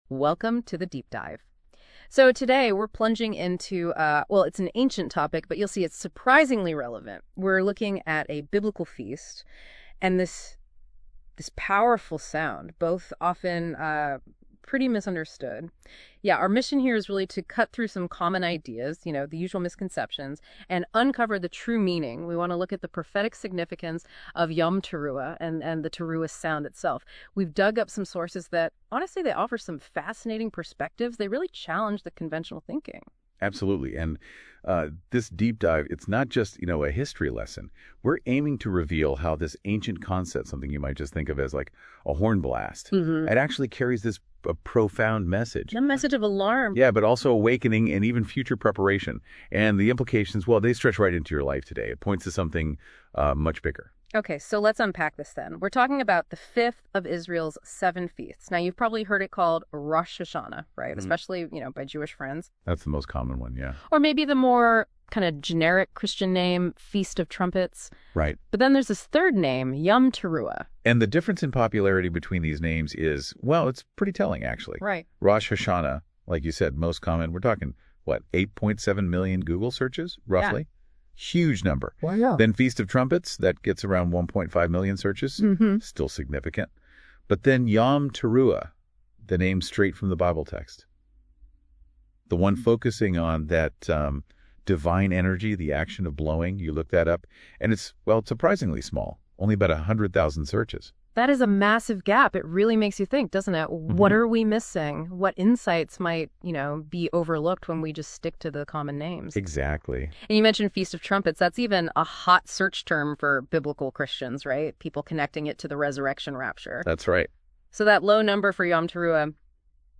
The AI Notebook LM Studio two person audio commentary on the video above.